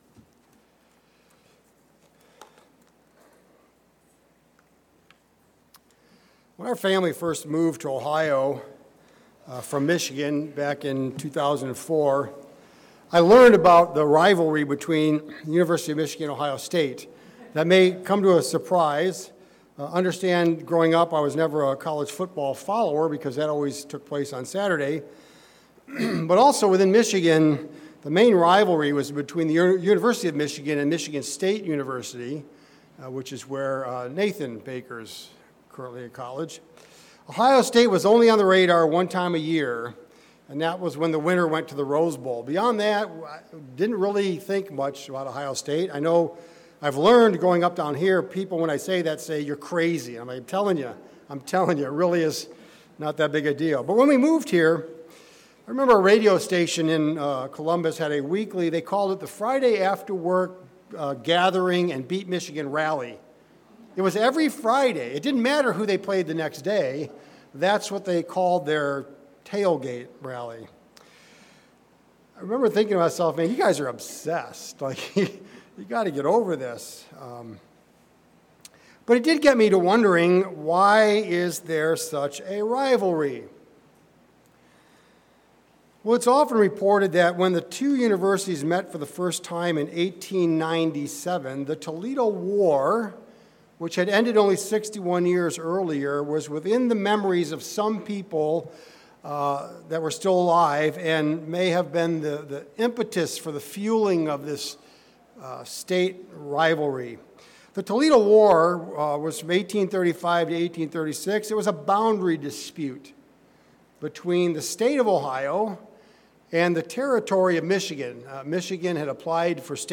Sermons
Given in Mansfield, OH